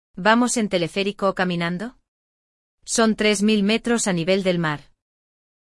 Hoje, você acompanhou a aventura de dois amigos colombianos ao subir uma montanha bem acima do nível do mar.
Diálogo e Tradução